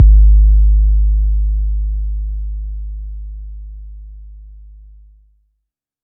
808 (Blow).wav